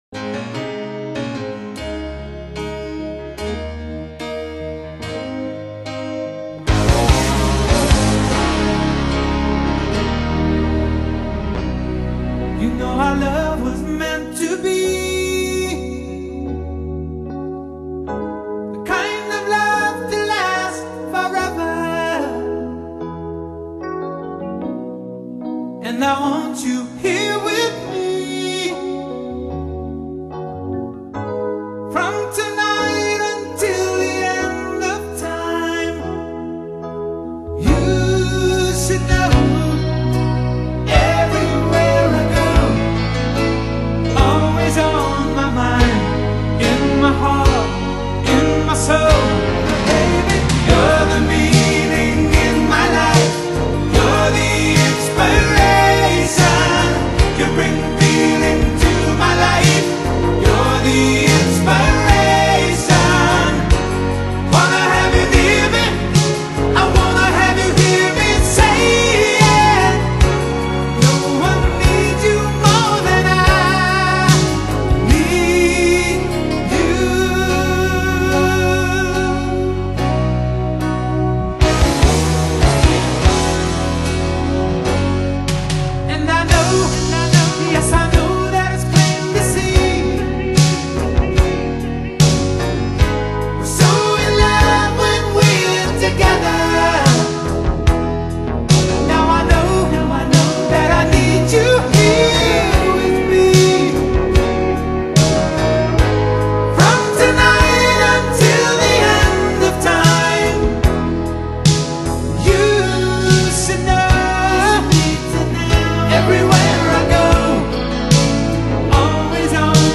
全球最受歡迎的都會情歌樂團